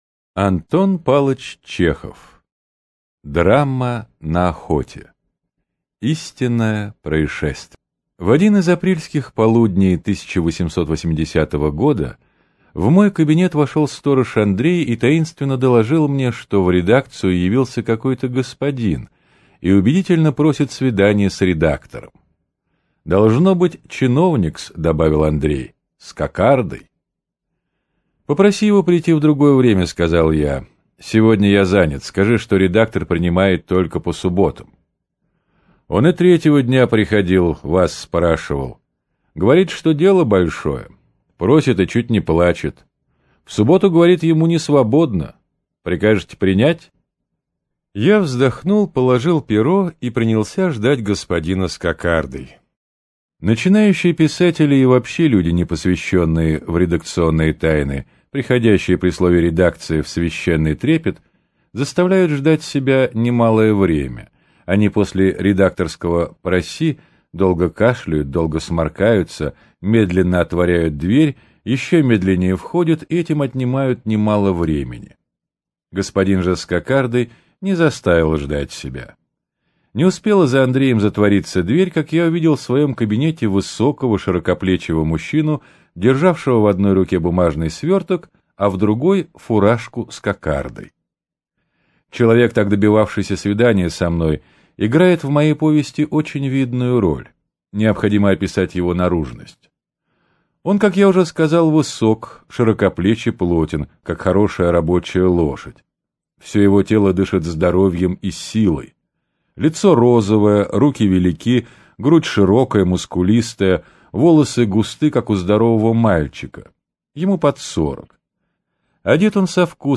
Аудиокнига Драма на охоте - купить, скачать и слушать онлайн | КнигоПоиск